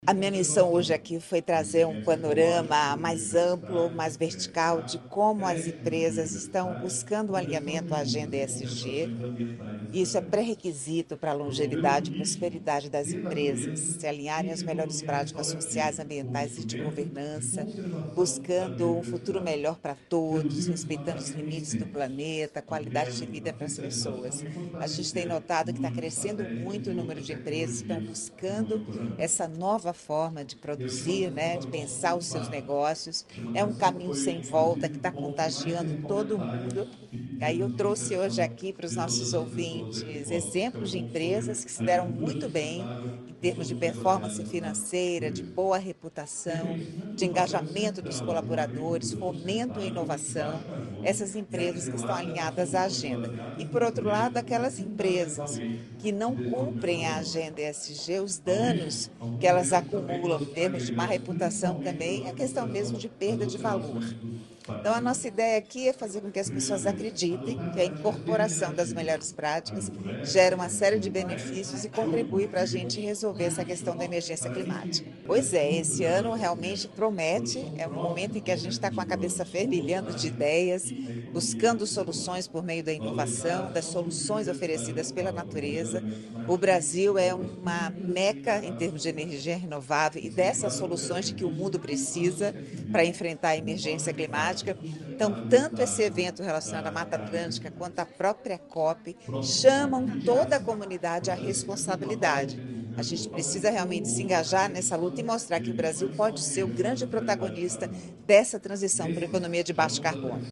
Sonora da jornalista Rosana Jatobá sobre o encerramento da Conferência da Mata Atlântica